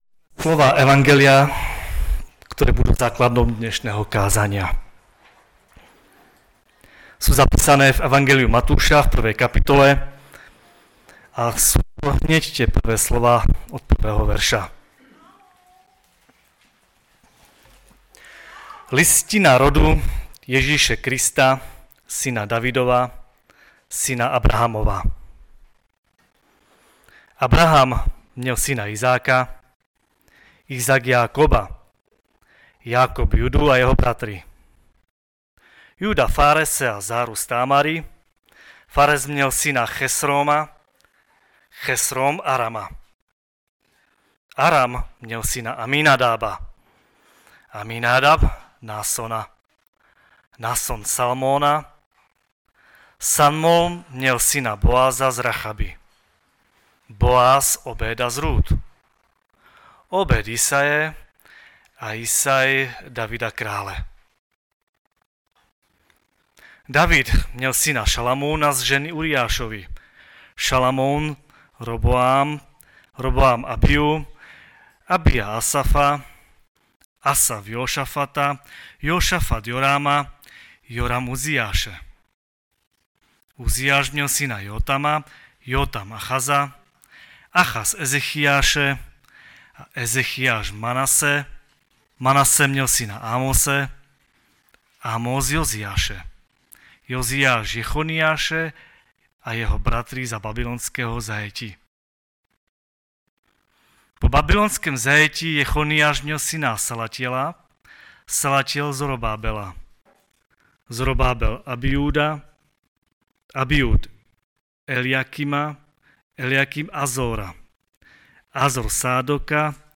Kazatel